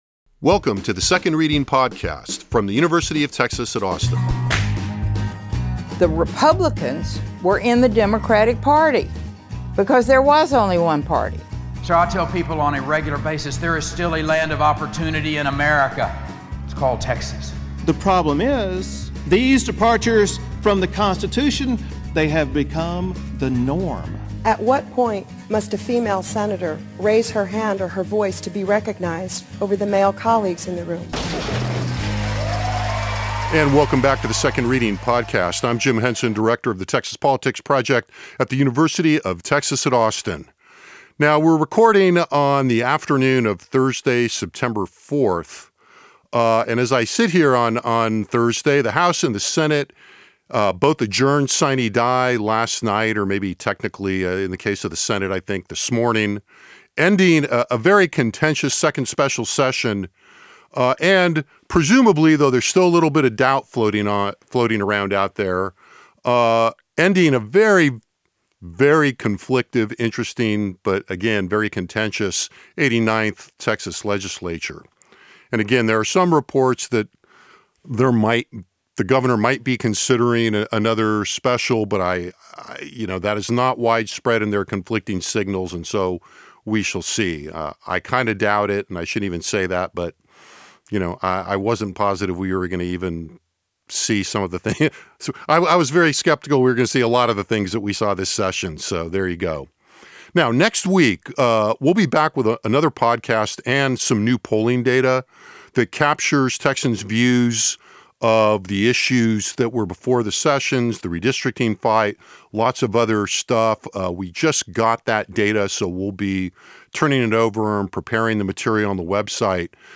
A PAAT panel on the special session + a sneak peak at new Texas Politics Project polling data
a-paat-media-panel-on-the-special-session-a-sneak-peak-at-new-texas-politics-project-polling-data.mp3